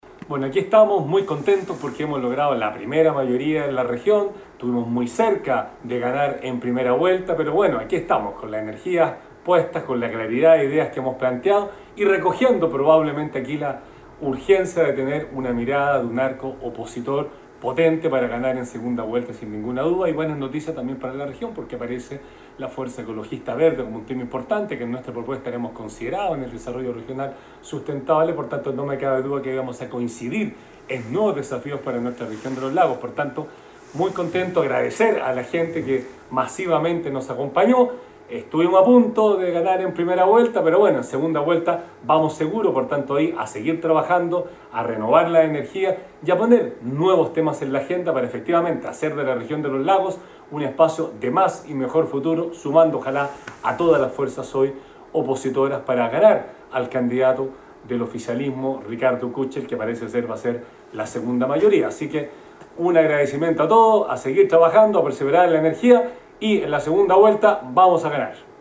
Esto fue lo señalado por el candidato Patricio Vallespin.